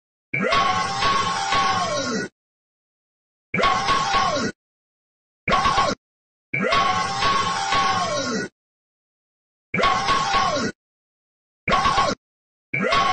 Category: Meme Soundboard